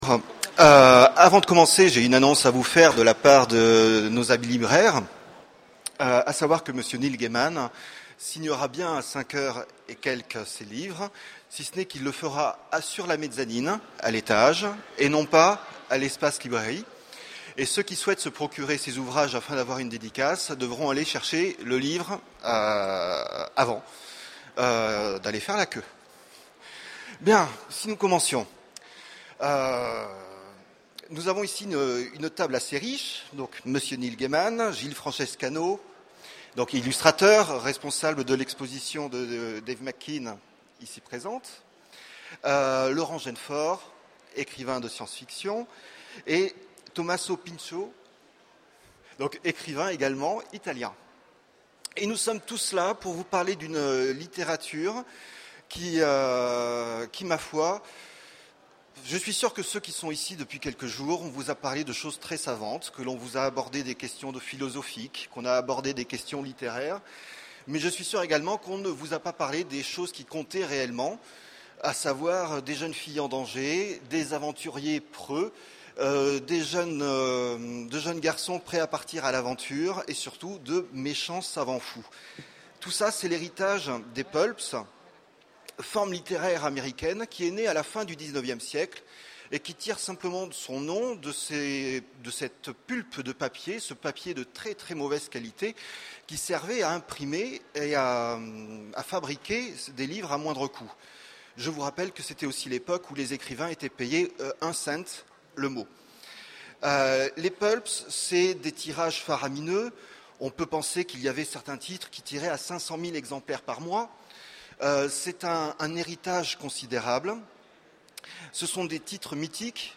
Utopiales 12 : Conférence Pulps !
- le 31/10/2017 Partager Commenter Utopiales 12 : Conférence Pulps !